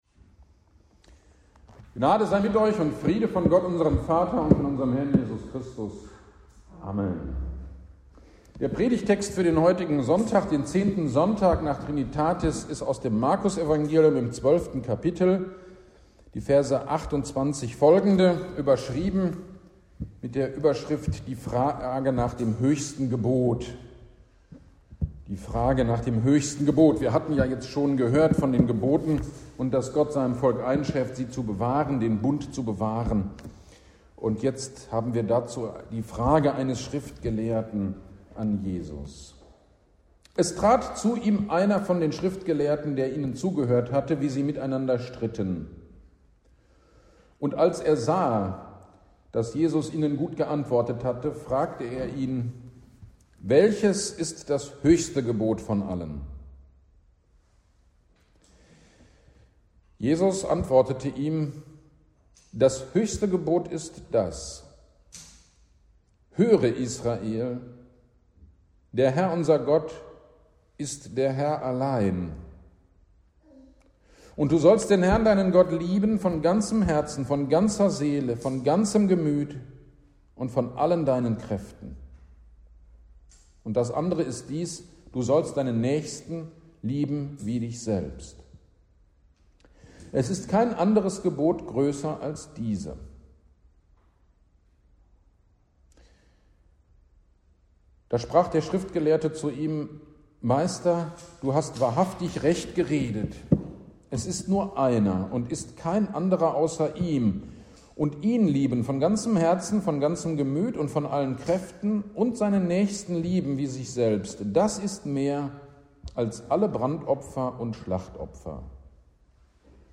GD am 13.08.23 Predigt zu Markus 12.28-34